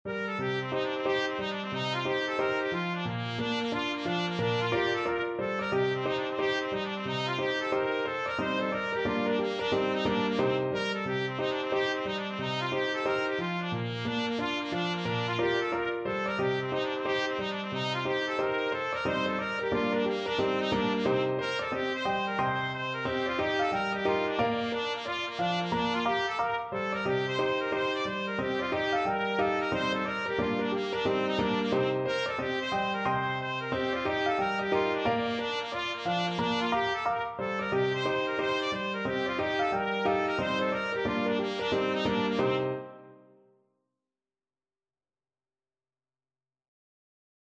Free Sheet music for Trumpet
Trumpet
Bb major (Sounding Pitch) C major (Trumpet in Bb) (View more Bb major Music for Trumpet )
Presto =c.180 (View more music marked Presto)
4/4 (View more 4/4 Music)
Bb4-D6
Traditional (View more Traditional Trumpet Music)
swallowtail_TPT.mp3